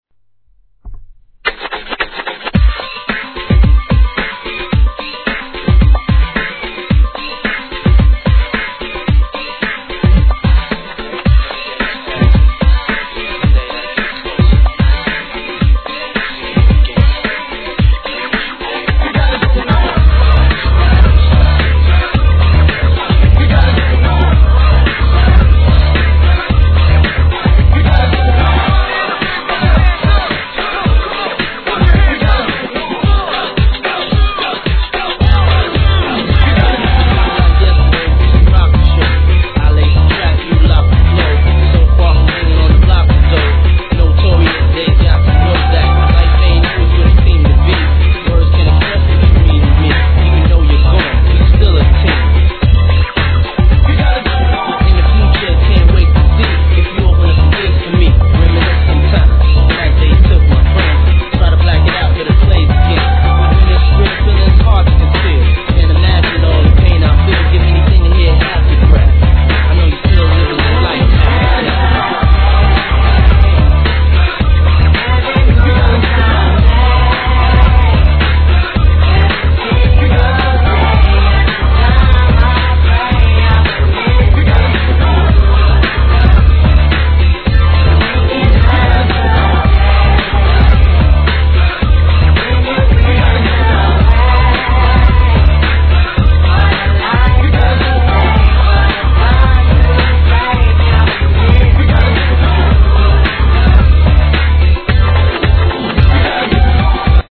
HIP HOP/R&B
PARTY使用へ変わった禁断のREMIXが遂にリリースです！！！